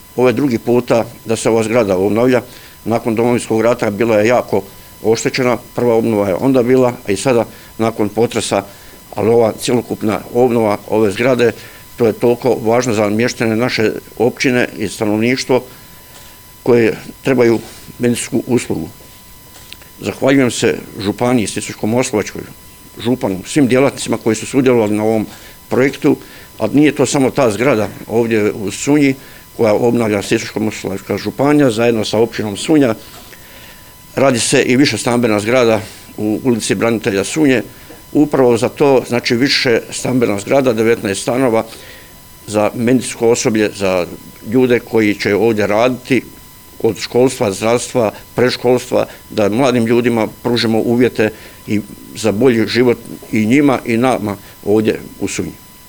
Načelnik Općine Sunja Grga Dragičević ističe veliki značaj ove ustanove za mještane ove općine